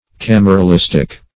Search Result for " cameralistic" : The Collaborative International Dictionary of English v.0.48: Cameralistic \Cam`e*ra*lis"tic\, a. Of or pertaining to finance and public revenue.
cameralistic.mp3